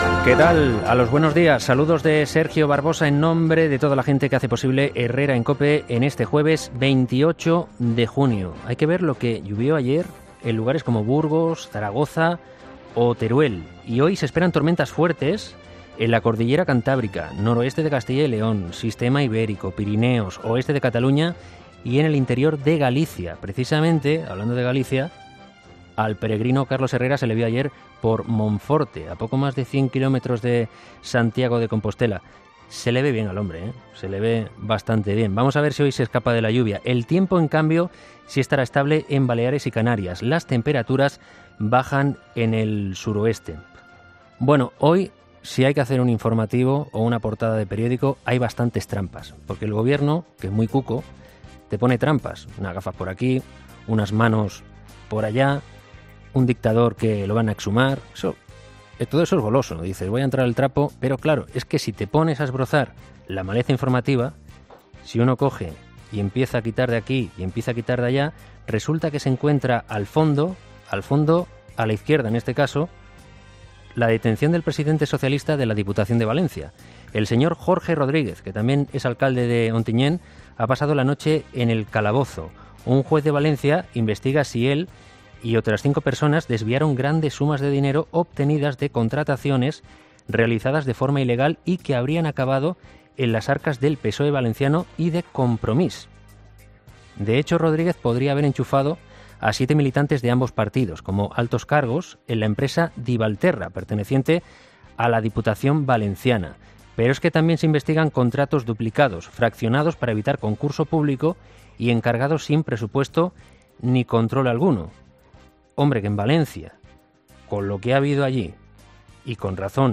Monólogo de las 8 de Herrera 'Herrera en COPE